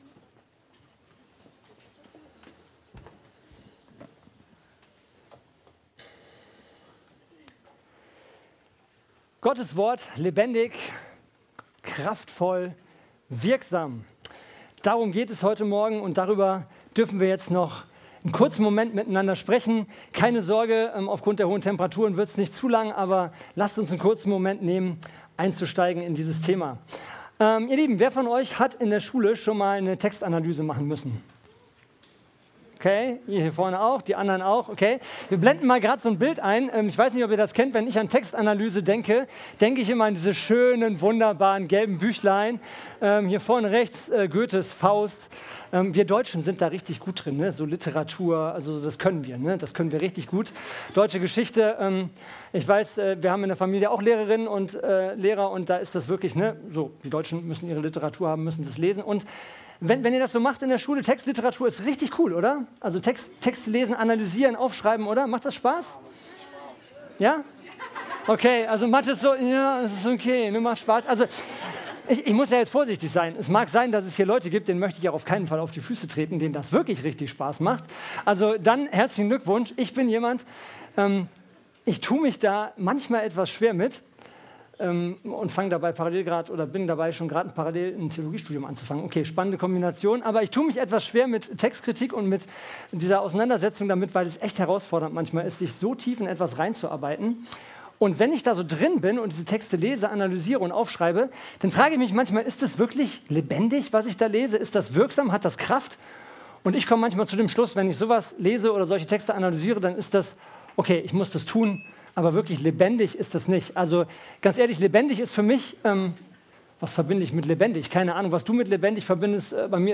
Die Online-Präsenz der freien evangelischen Andreas-Gemeinde Osnabrück
PREDIGTEN